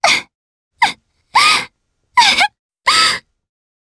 Frey-Vox_Sad_jp.wav